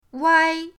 wai1.mp3